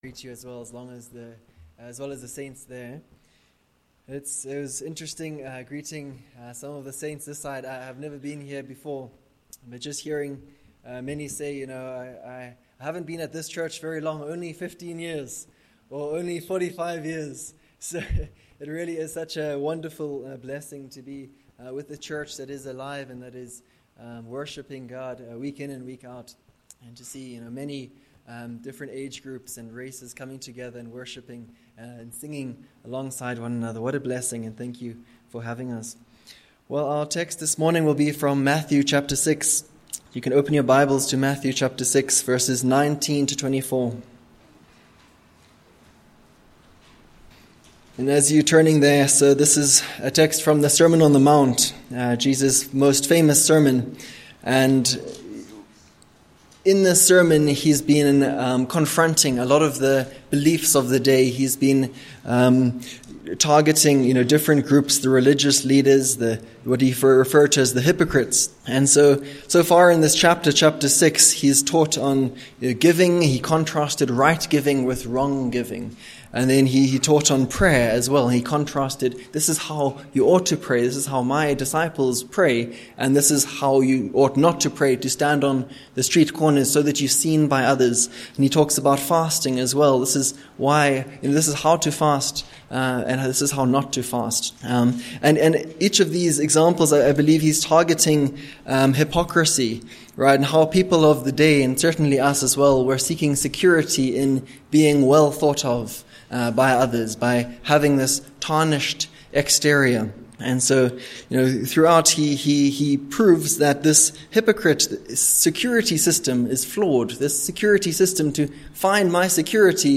Passage: Matthew 6v19-24 Service Type: Morning